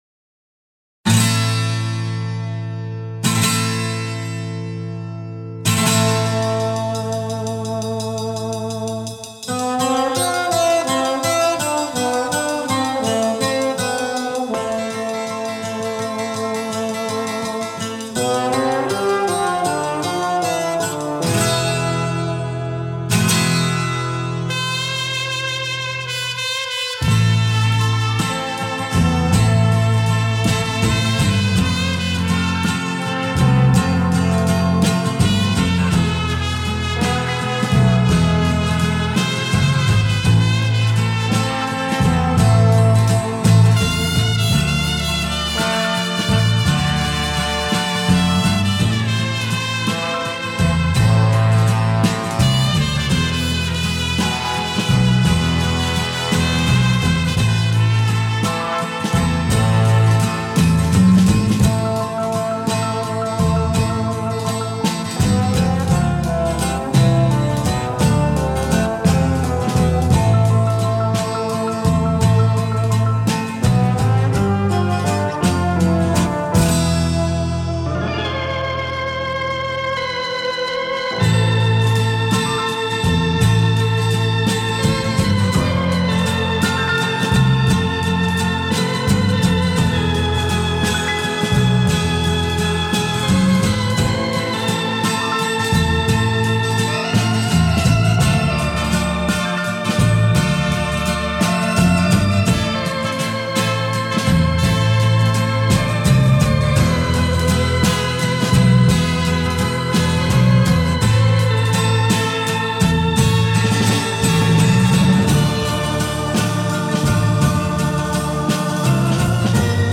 Отличная инструменталка!
Я снял звук с Ютуба и небольшой коррекцией выкладу тоже.